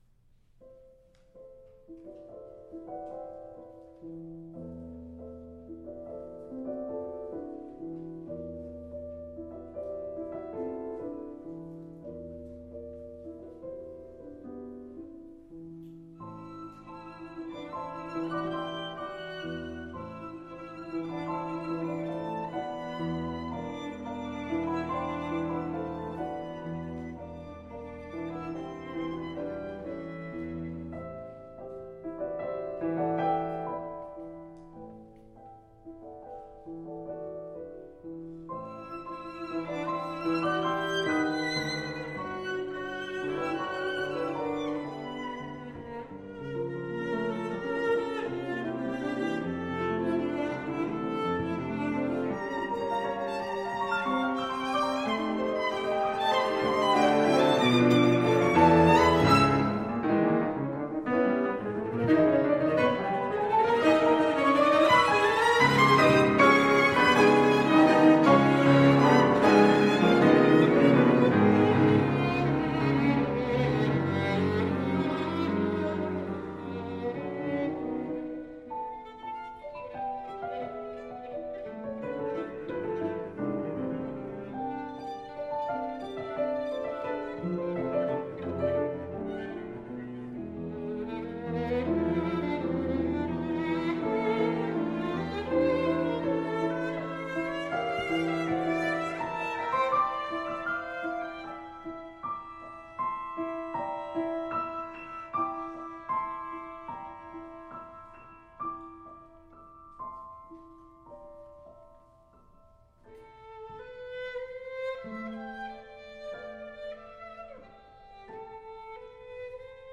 Instrument: Piano Trio
Style: Classical
Audio: Boston - Isabella Stewart Gardner Museum
violin
cello
piano